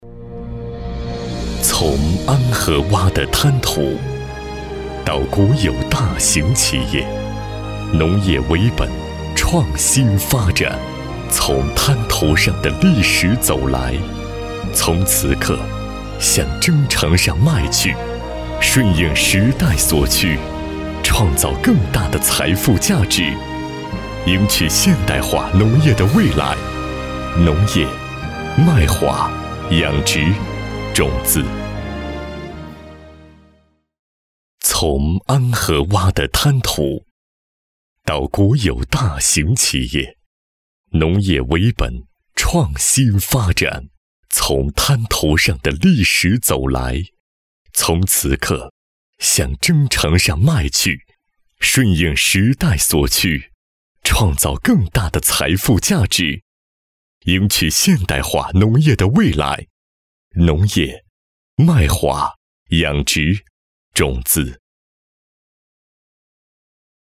男162-- 包钢集团万开实业有限公司
擅长：专题片 广告
特点：大气浑厚 稳重磁性 激情力度 成熟厚重
风格:浑厚配音